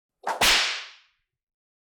Download Whipping sound effect for free.
Whipping